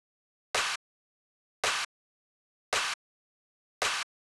35 Clap.wav